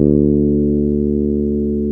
D SUS.wav